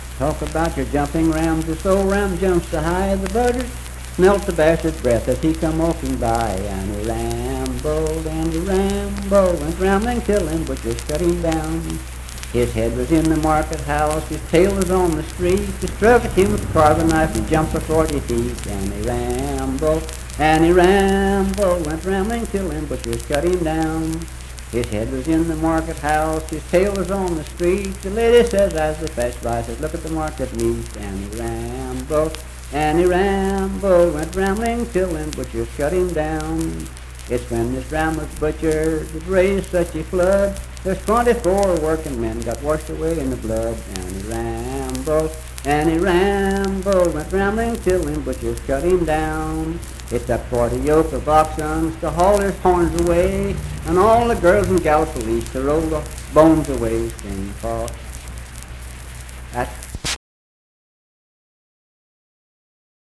Unaccompanied vocal music performance
Verse-refrain 5(6w/R).
Voice (sung)